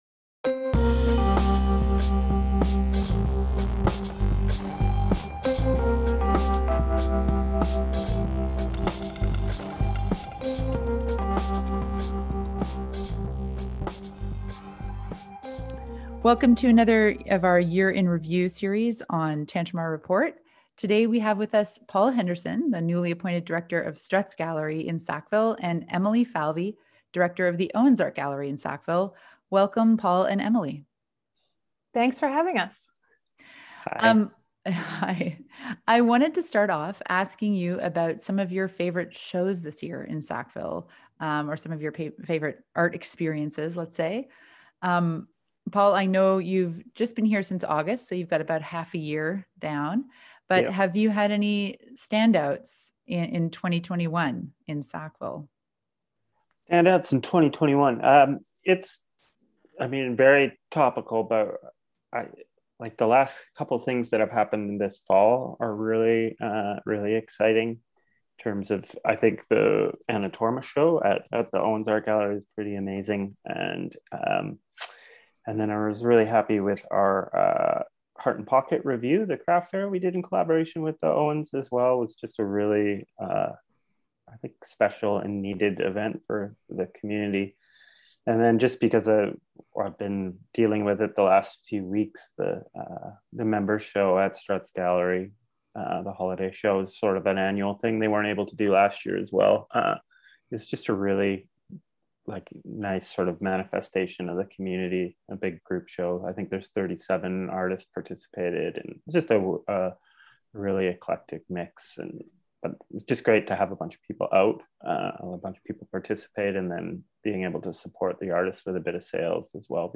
a conversation about the arts scene in Sackville and New Brunswick, touching on the impacts of the pandemic, and also New Brunswick’s low profile in the arts, and what it means for artists and audiences.